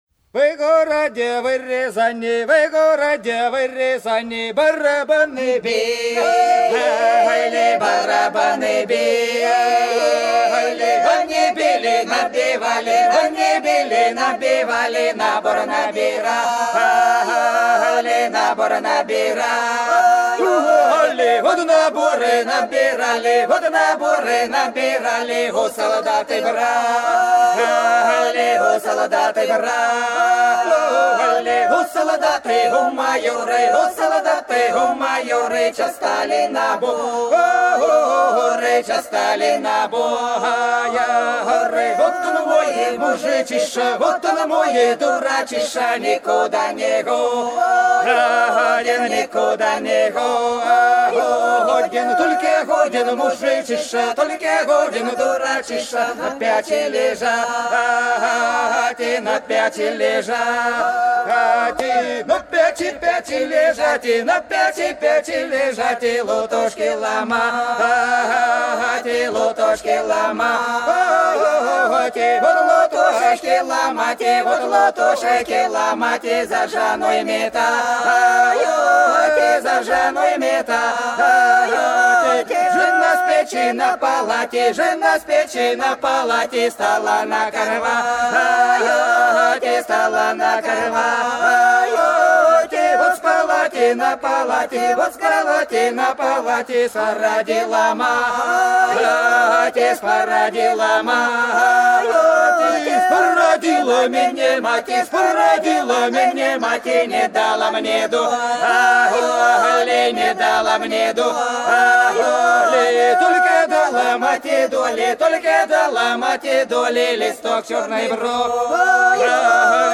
Долина была широкая (Поют народные исполнители села Нижняя Покровка Белгородской области) Во городе, во Рязани - плясовая